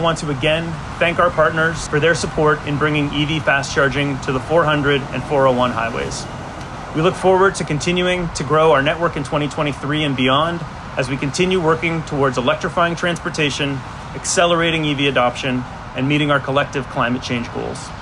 They gathered at the westbound Trenton ONroute to announce that fast chargers had been installed at all ONroutes, ahead of the summer tourist season.